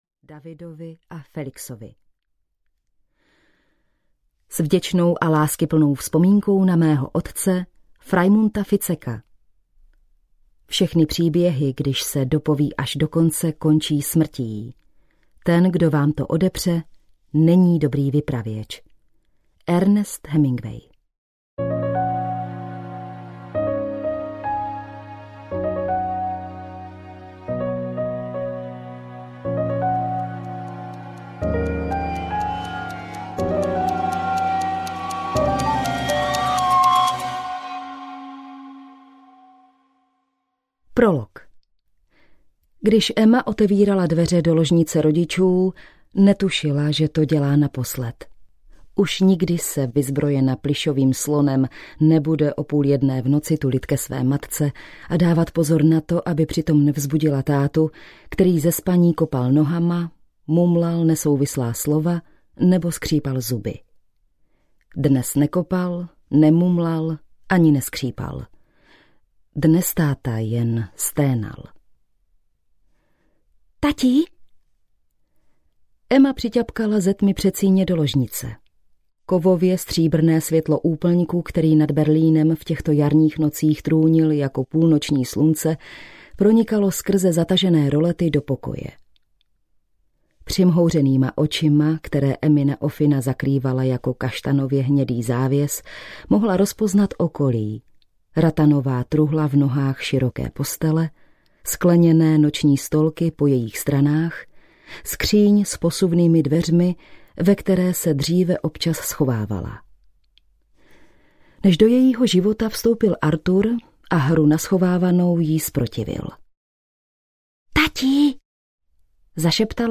Audio knihaBalíček
Ukázka z knihy